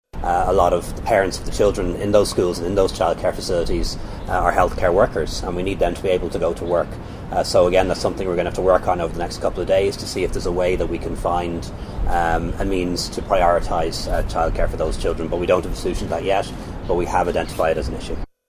Taoiseach Leo Varadkar says they haven’t yet found the solution: